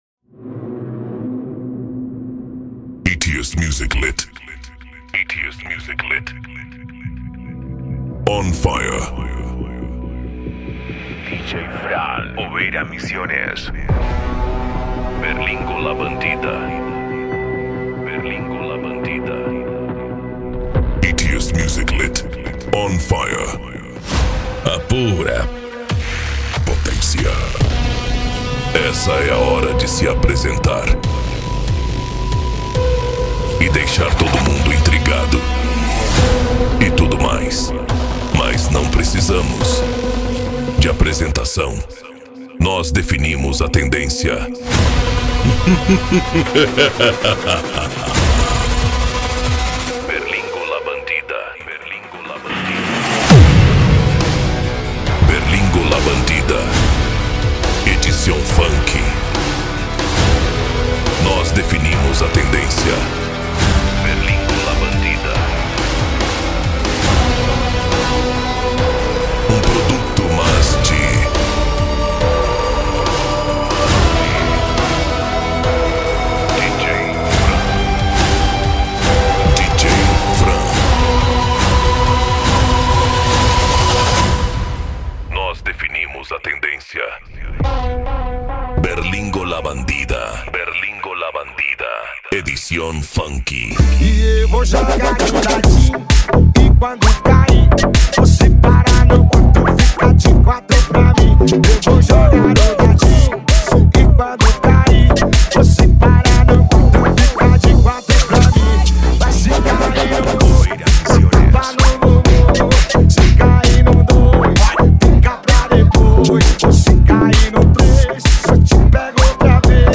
Funk
Mega Funk
Remix